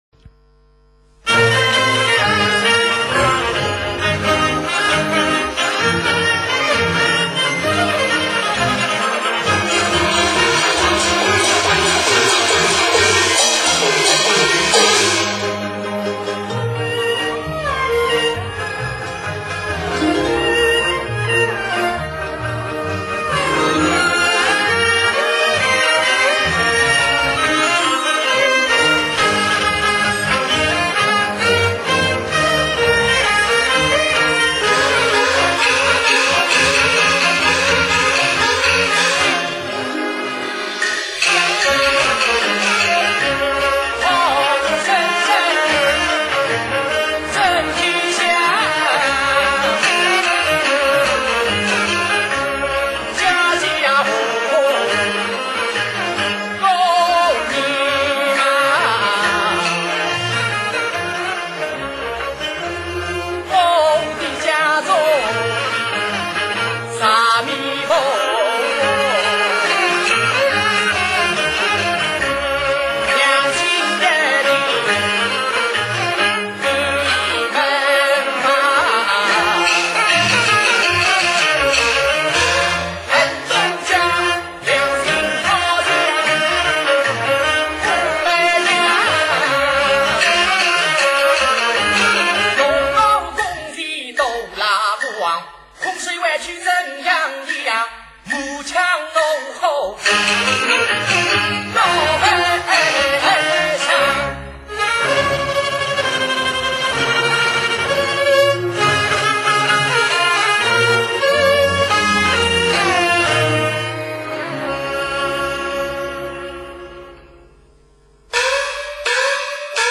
[4/21/2008]锡剧《双推磨》全本